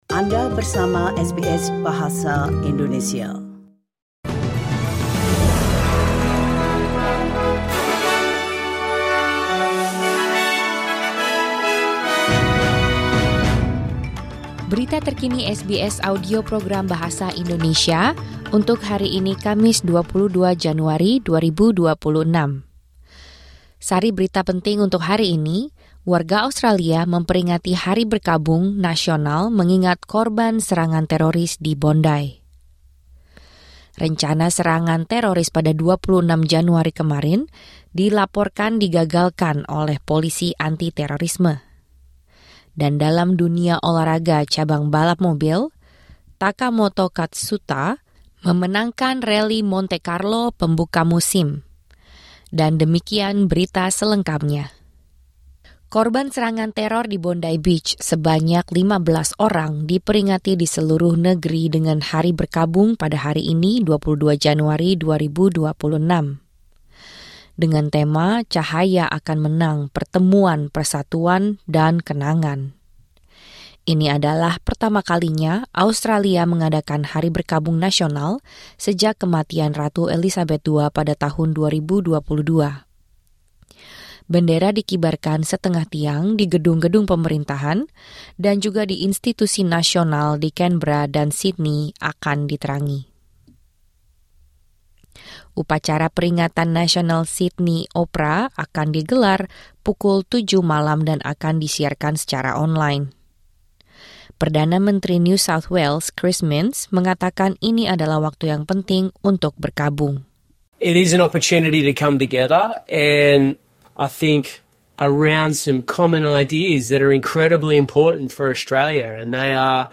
Berita Terkini SBS Audio Program Bahasa Indonesia - Kamis 22 Januari 2026